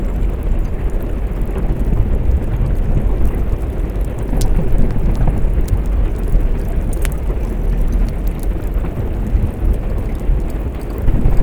sfx_lavafield.wav